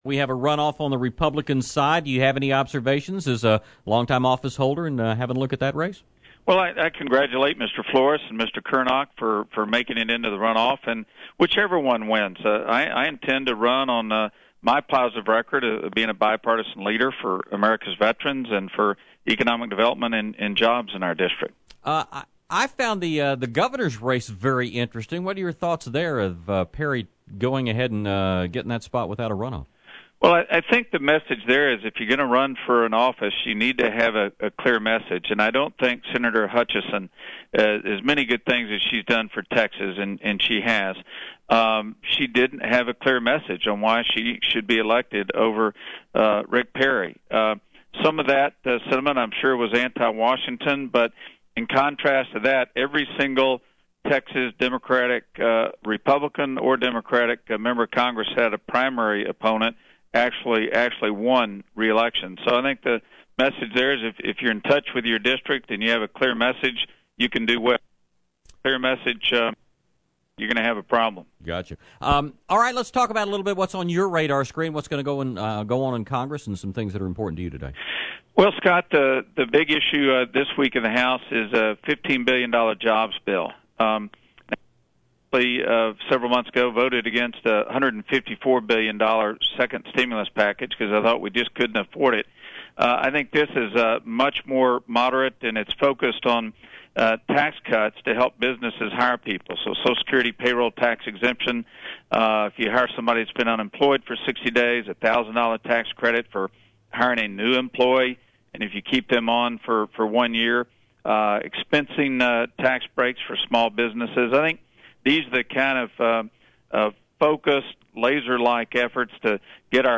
Chet Edwards Interview – March 3, 2010